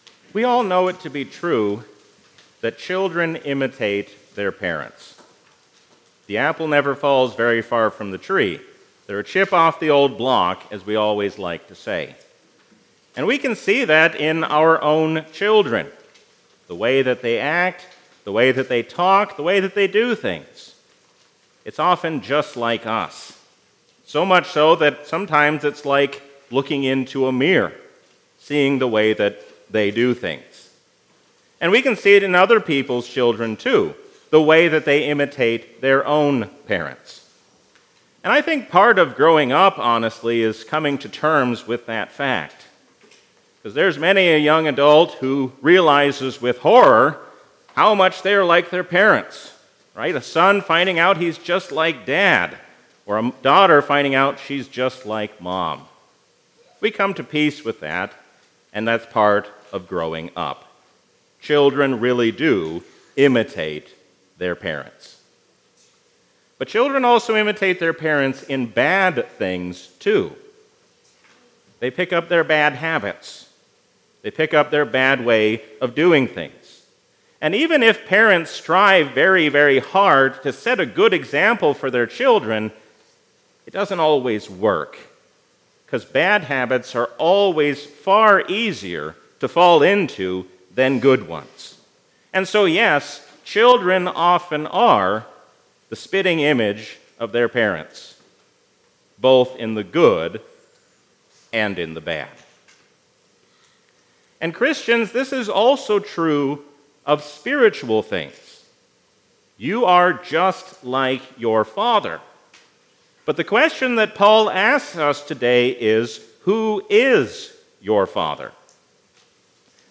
A sermon from the season "Trinity 2023." Jesus shows us what it means to follow after God by showing us how to show mercy to those in need.